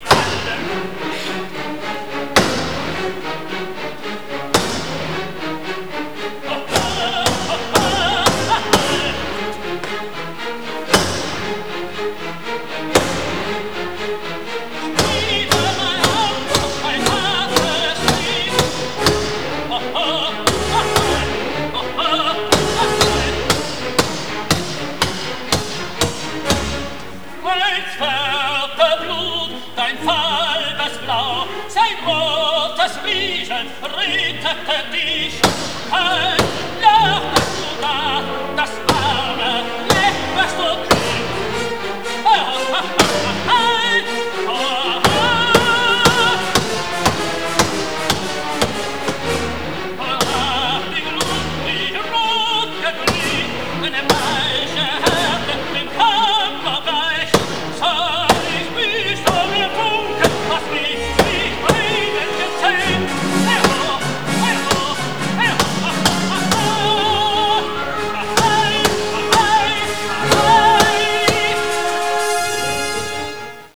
Das macht Siegfried dann persönlich - unter brausendem Gesang ("
schmiede.wav